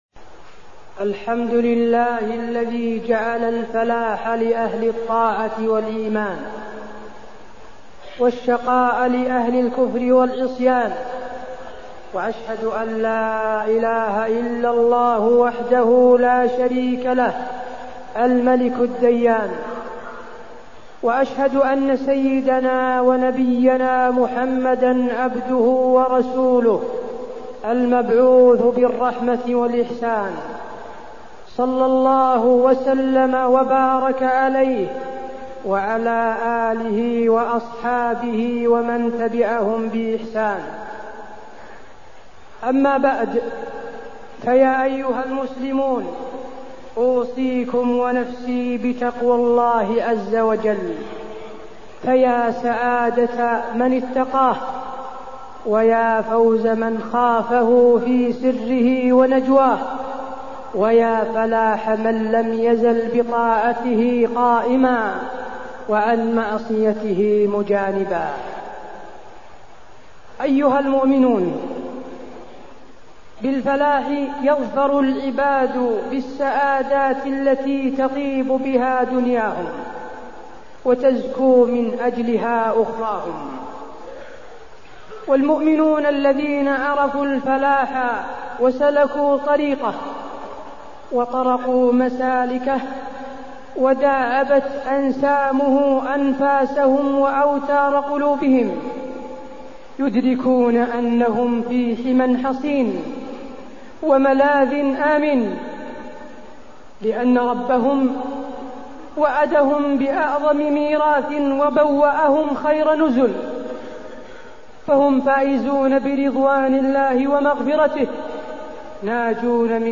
تاريخ النشر ١٤ جمادى الآخرة ١٤٢٠ هـ المكان: المسجد النبوي الشيخ: فضيلة الشيخ د. حسين بن عبدالعزيز آل الشيخ فضيلة الشيخ د. حسين بن عبدالعزيز آل الشيخ صفات المؤمنين